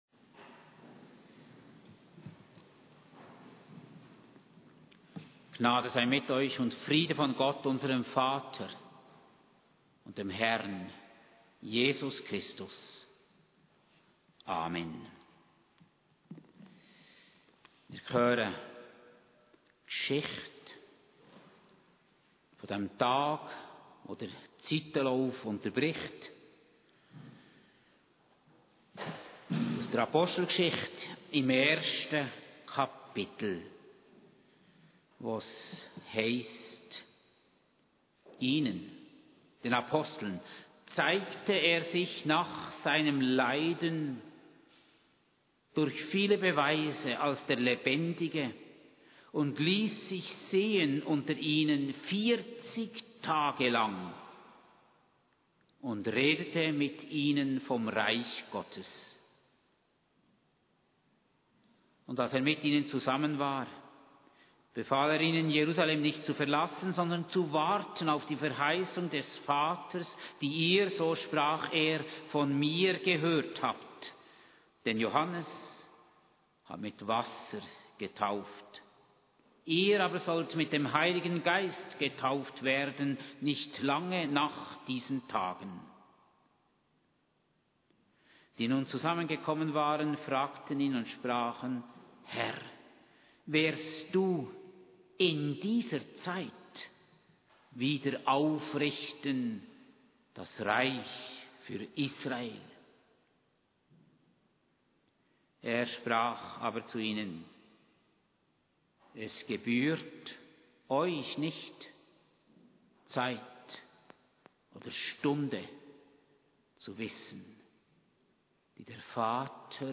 Predigt aus Apostelgesichte 1,3-11 im Abendmahlsgottesdienst an Christi Himmelfahrt, 29. Mai 2025, in Hundwil